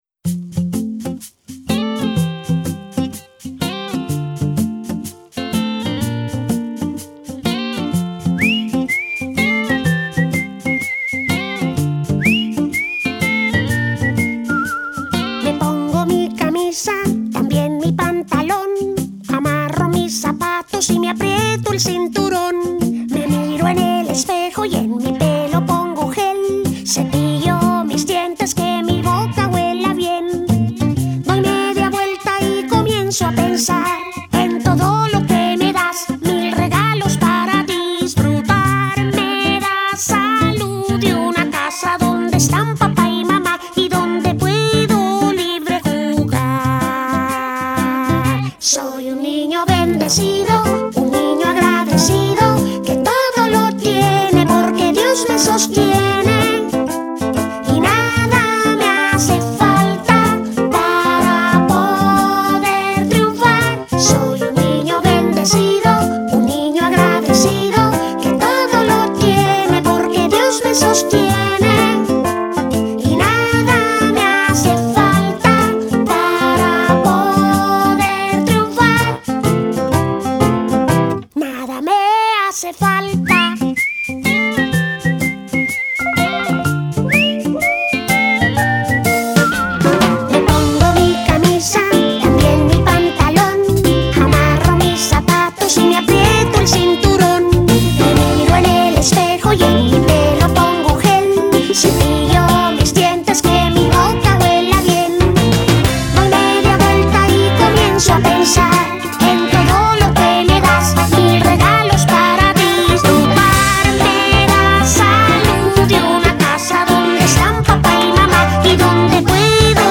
Reconozco que me siento un tanto incómodo haciendo el papel de un niño.